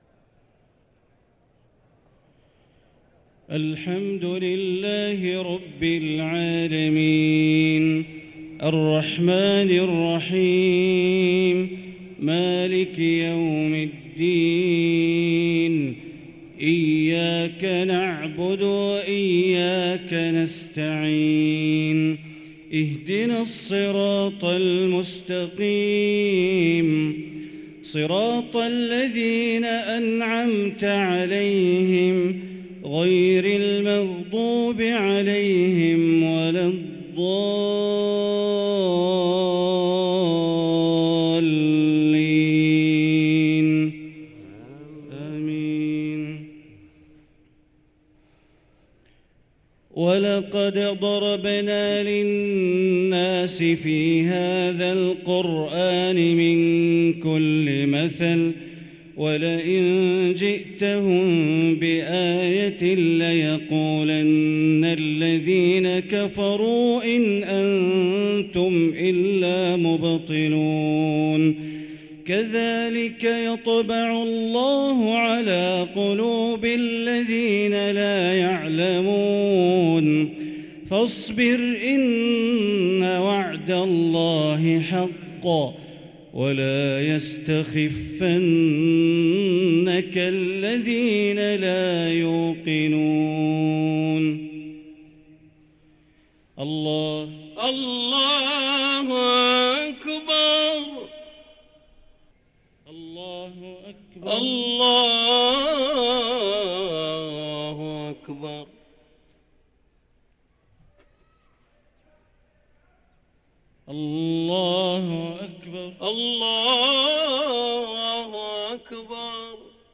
صلاة العشاء للقارئ بندر بليلة 2 رمضان 1444 هـ
تِلَاوَات الْحَرَمَيْن .